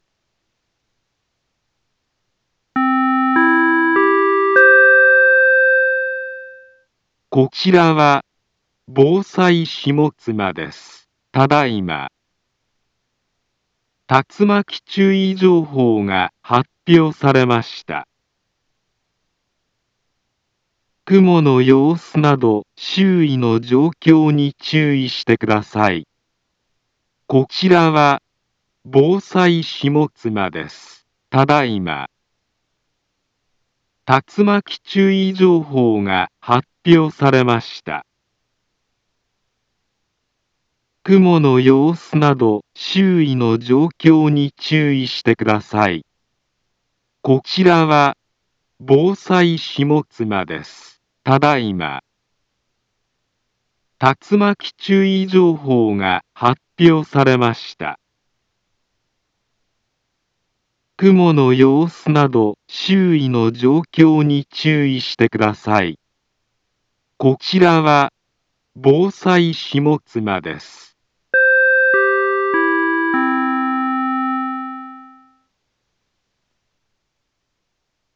Back Home Ｊアラート情報 音声放送 再生 災害情報 カテゴリ：J-ALERT 登録日時：2024-09-18 15:29:22 インフォメーション：茨城県北部、南部は、竜巻などの激しい突風が発生しやすい気象状況になっています。